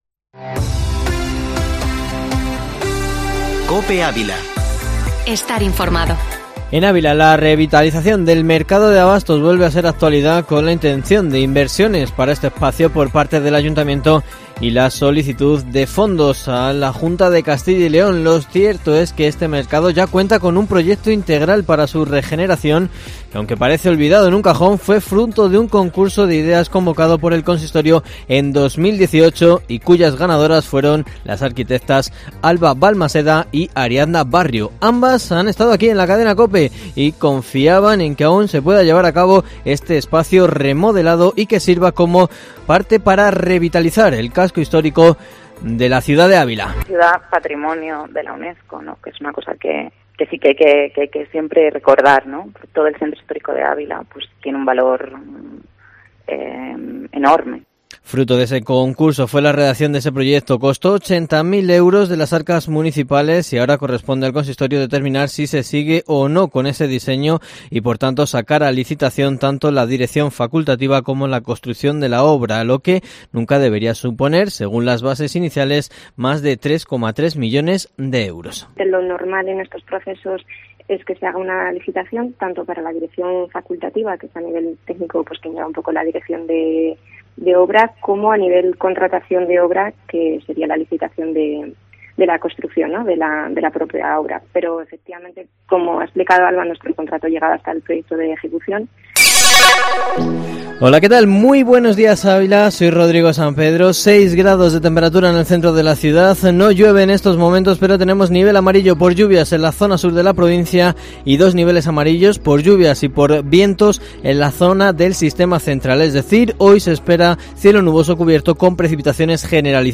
Informativo matinal Herrea en COPE Ávila 09/02/2021